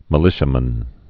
(mə-lĭshə-mən)